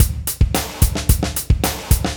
Drumsトラックに１小節分のグルーブ・ループを貼り付け、４小節に伸ばしたところです。
サンプルWAV オリジナル・テンポ/キー ＝ 110 / C （ACID 290KB）
drum01_loop.wav